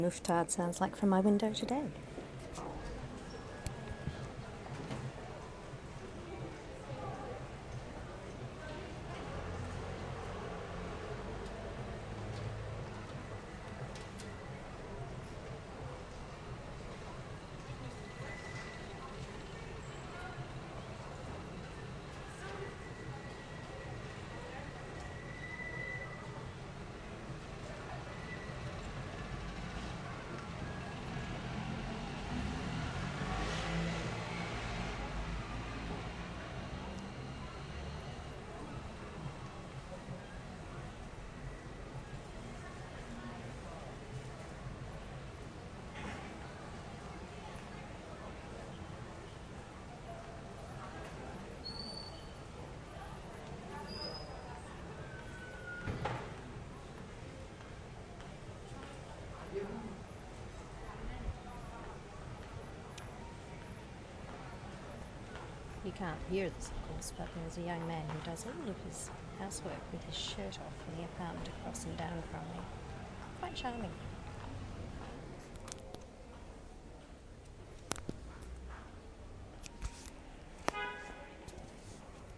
Out the window - Rue Mouffetard 1